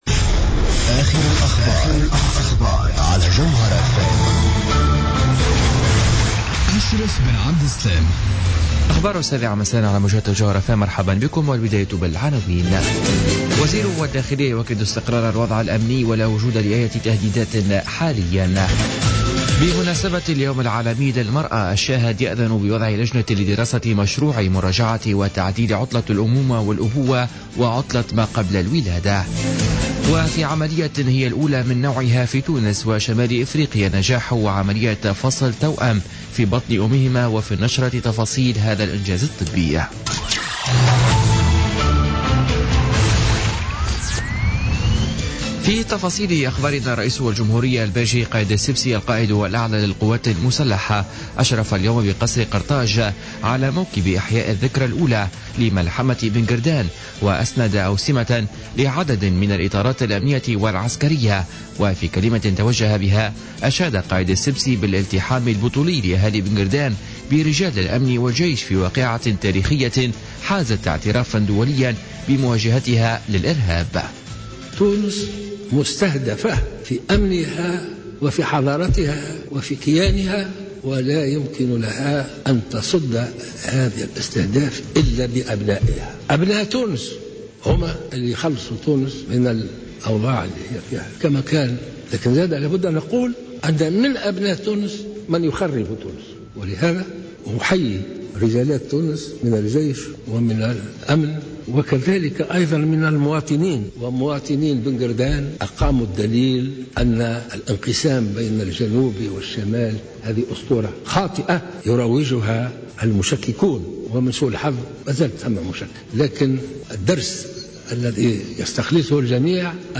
نشرة أخبار السابعة مساء ليوم الأربعاء 8 مارس 2017